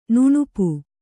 ♪ nuṇupu